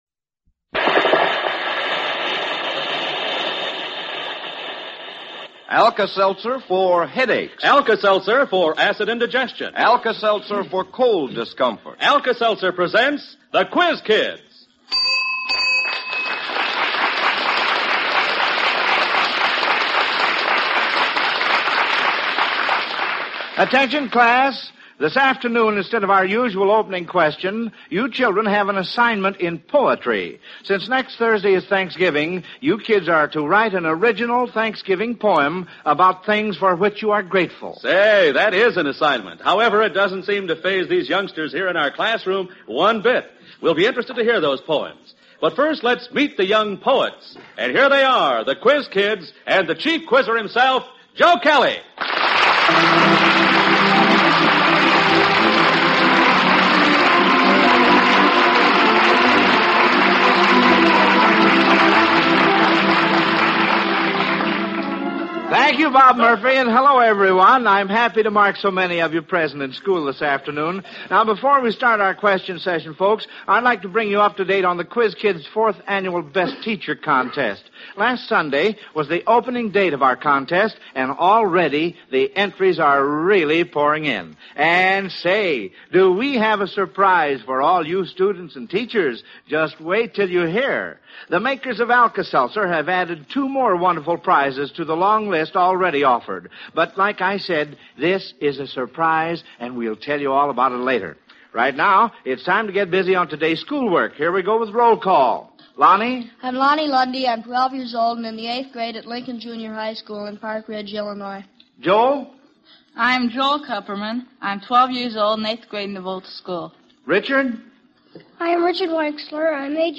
The Quiz Kids Radio Program